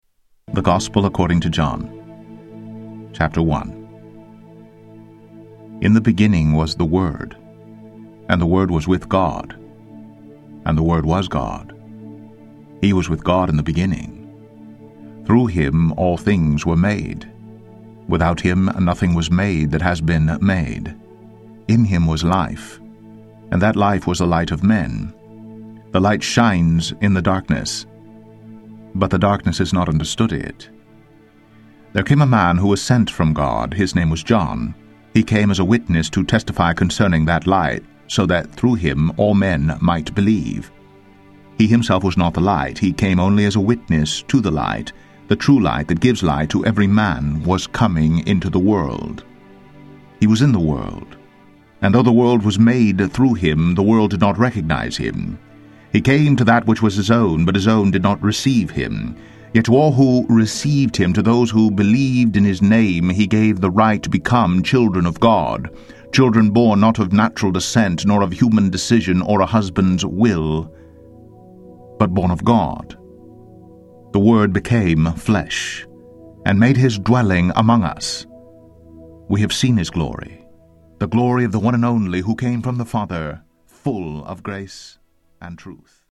Tags: Christian Books Audio books Christian Audio books Media